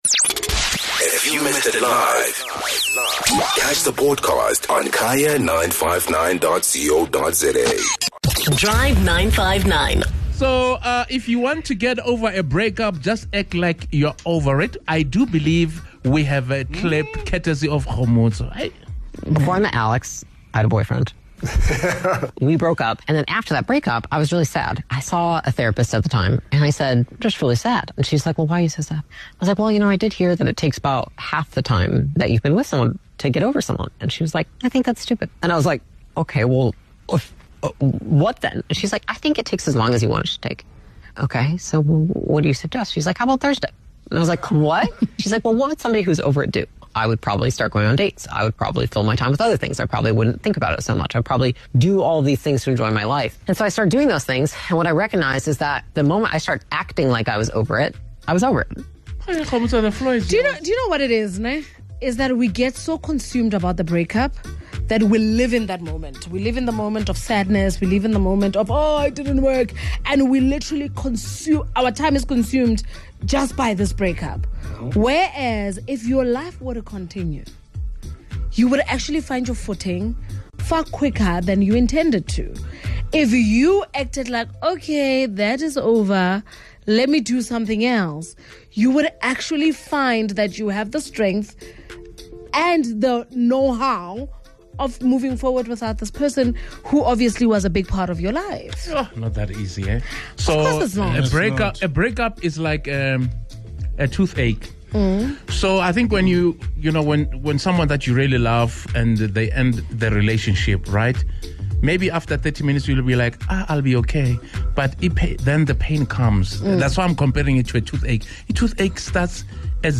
Check out what the Drive 959 team and our listeners had to say!